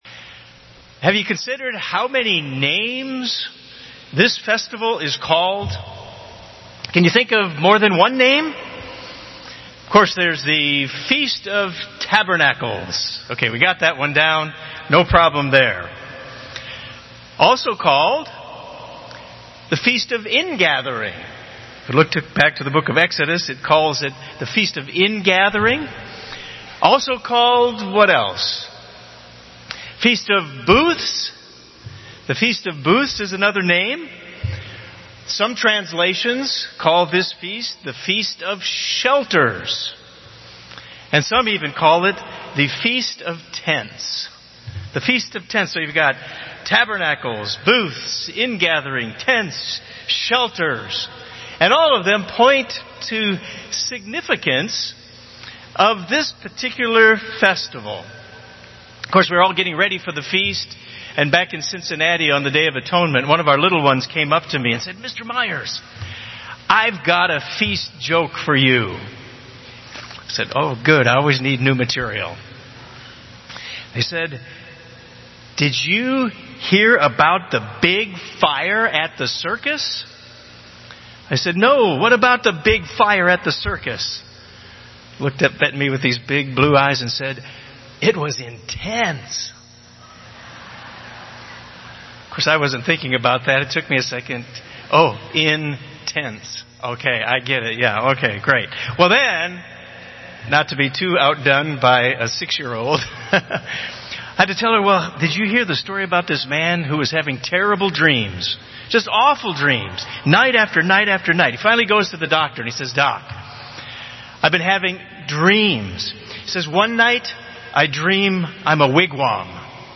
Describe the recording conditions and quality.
This sermon was given at the Wisconsin Dells, Wisconsin 2017 Feast site.